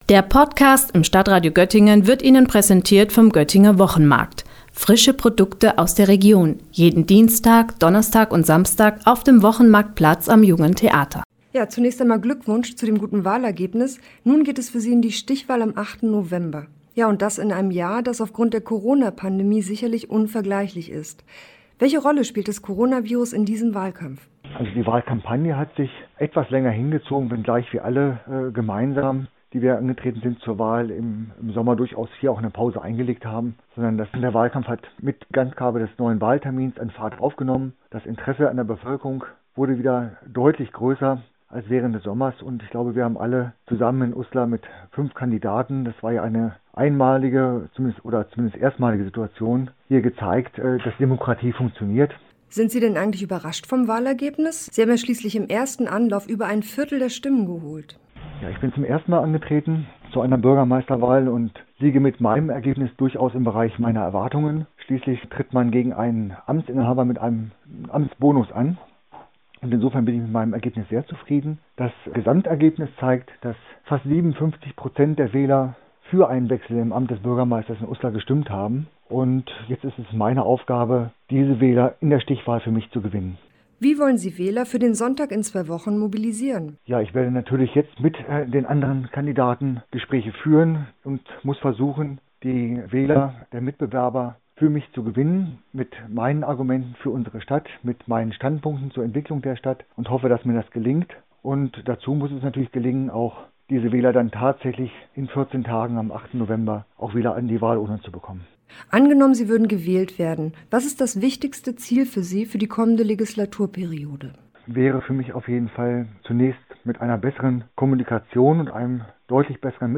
Kandidat zur Stichwahl